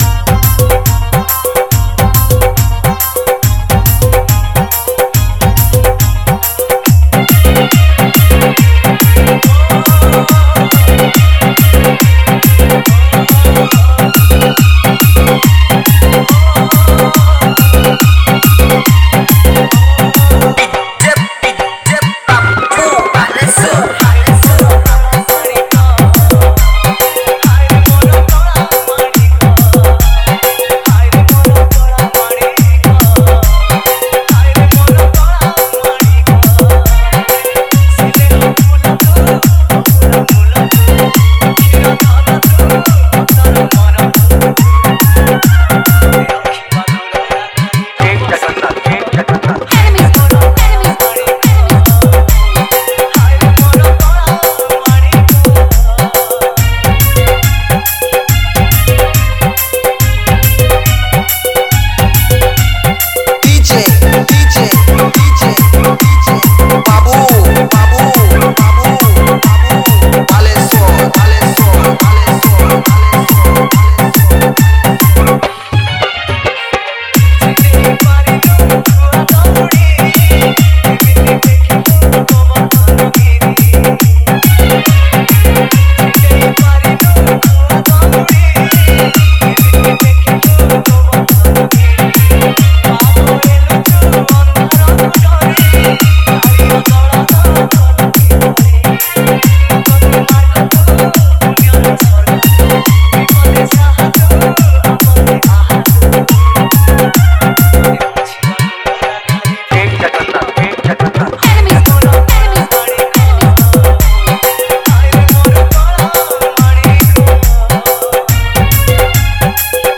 Category:  Odia Bhajan Dj 2020